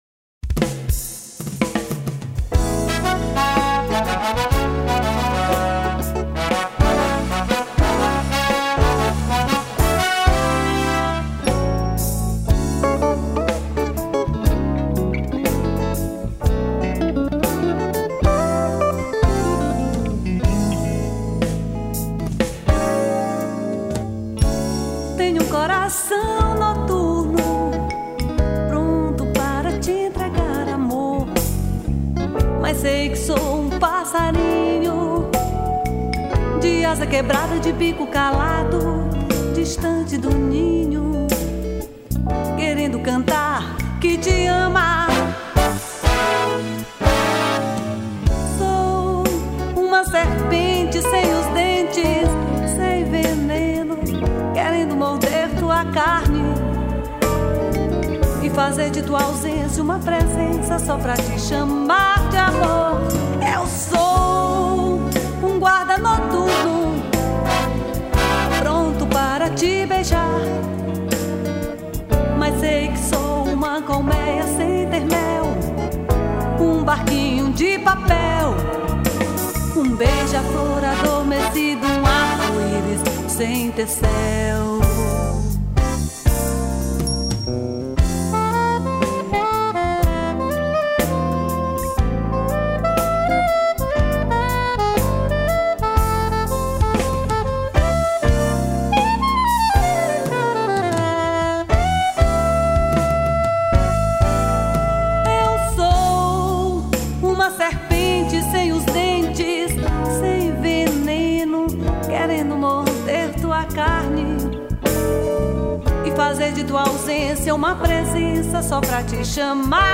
289   02:47:00   Faixa:     Canção
Guitarra
Piano Acústico, Teclados
Bateria
Sax Soprano